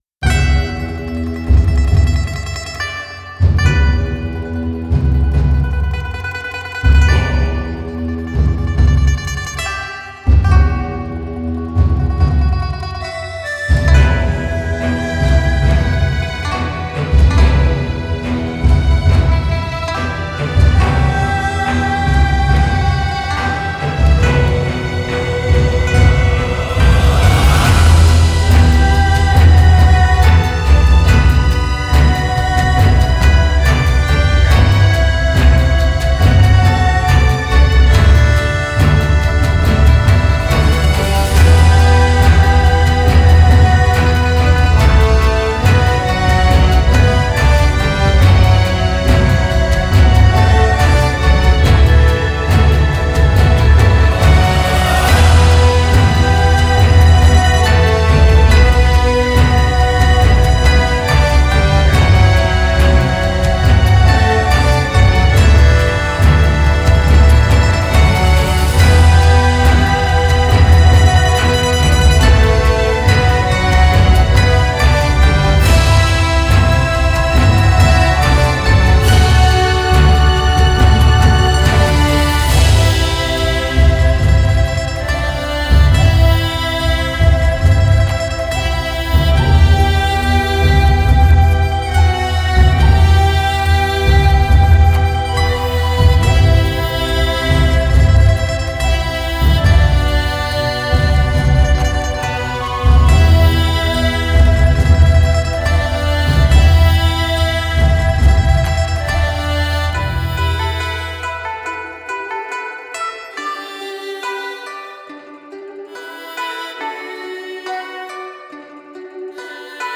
• 40 Strings Loops
• 60 Pure China Instrument Loops
• 10 Drum Loops
• 10 Brass Loops
• 10 Choir Loops
• 70 BPM